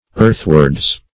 Definition of earthwards.
Search Result for " earthwards" : The Collaborative International Dictionary of English v.0.48: Earthward \Earth"ward\, Earthwards \Earth"wards\, adv.